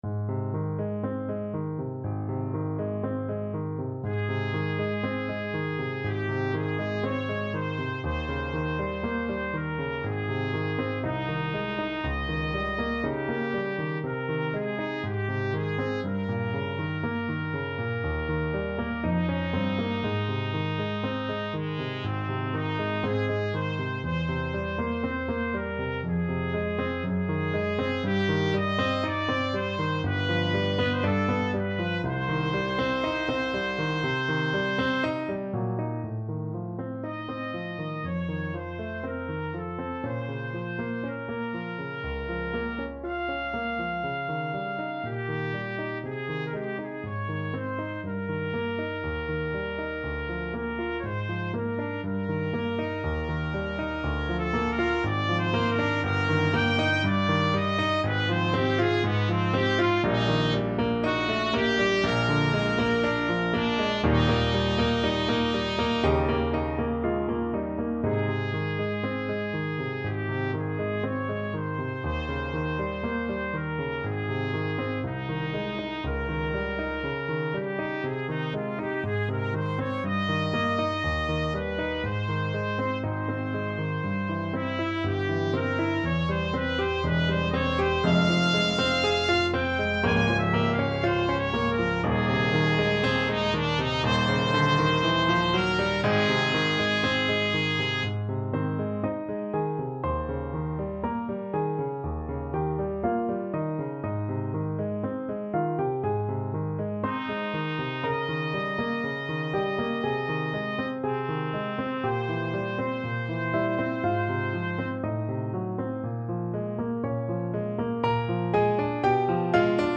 ~ = 100 Andante quasi Adagio
4/4 (View more 4/4 Music)
Ab major (Sounding Pitch) Bb major (Trumpet in Bb) (View more Ab major Music for Trumpet )
F#4-Ab6
Trumpet  (View more Advanced Trumpet Music)
Classical (View more Classical Trumpet Music)